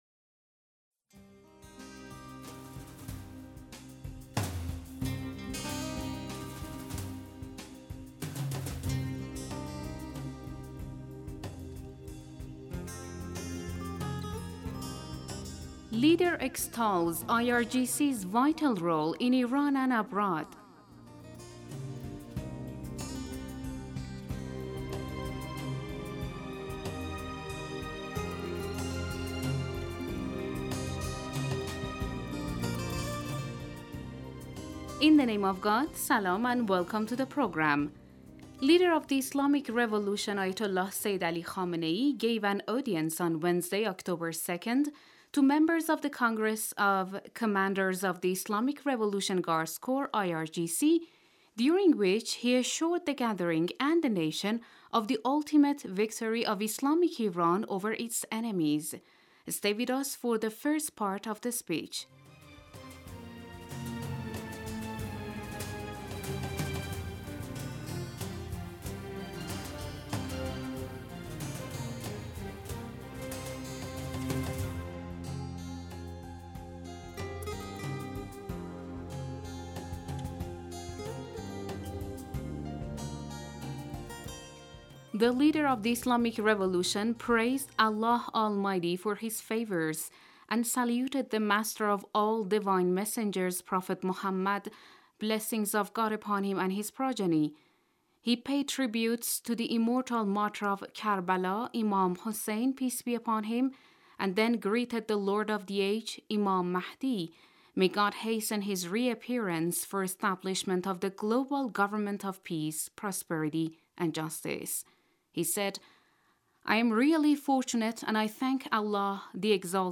The following is the first part of the speech: The Leader of the Islamic Revolution praised Allah Almighty for His Favours, and saluted the Master of the all Divine Messengers, Prophet Mohammad (blessings of God upon him and his progeny).